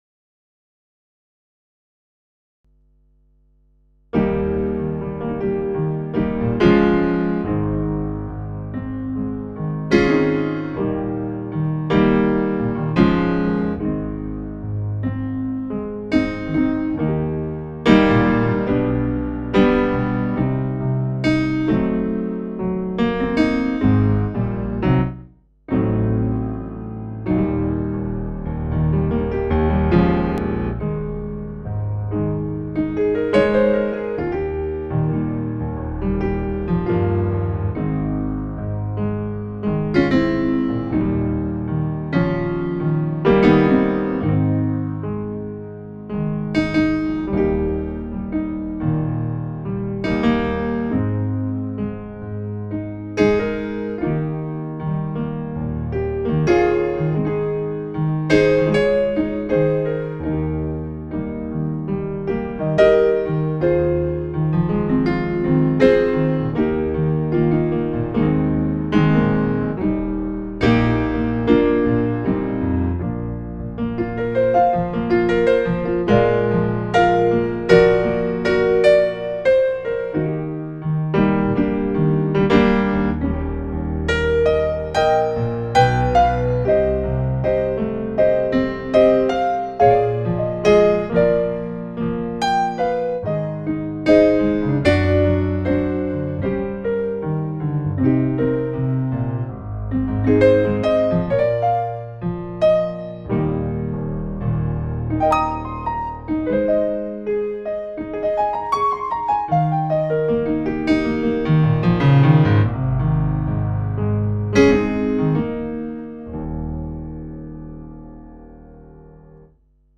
So here is a new piano I've worked on for the Kurzes